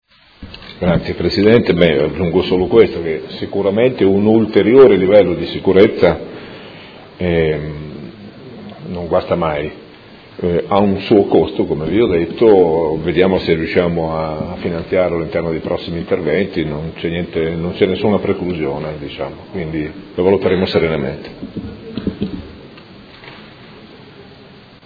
Seduta del 1/12/2016 Interrogazione del Gruppo Movimento cinque Stelle avente per oggetto: Sottopasso di Via Respighi. Conclusioni